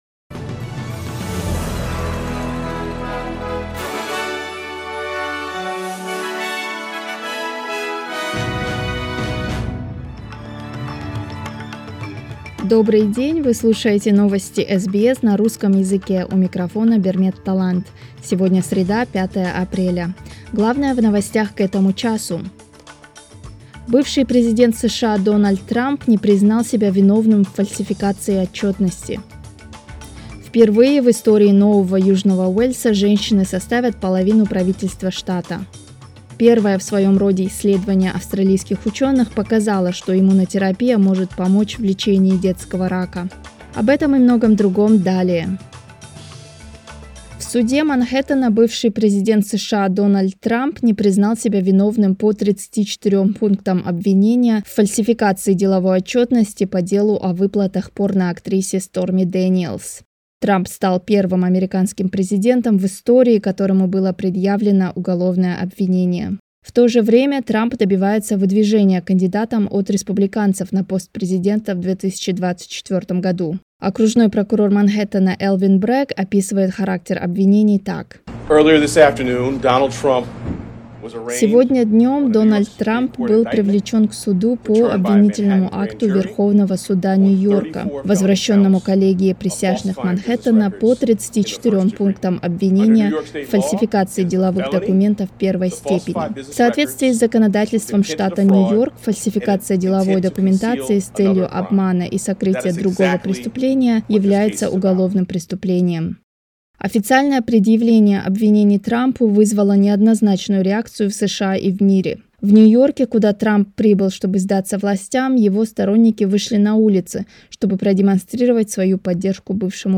SBS news in Russian—05.04.2023